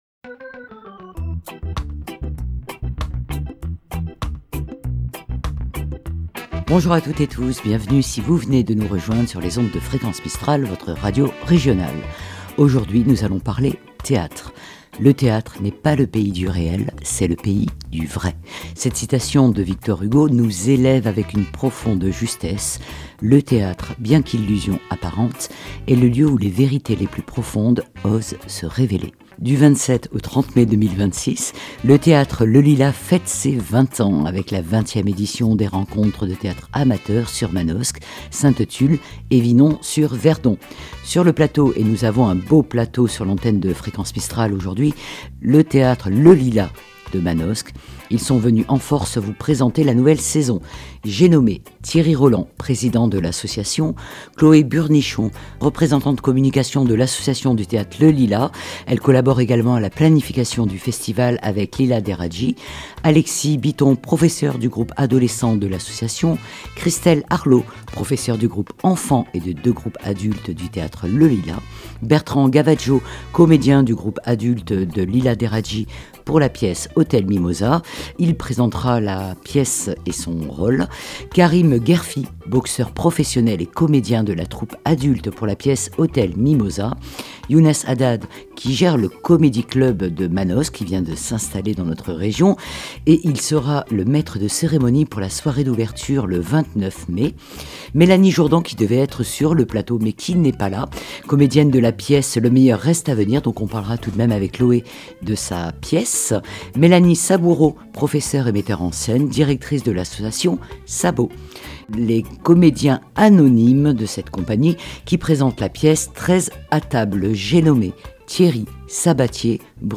Au micro de Fréquence Mistral :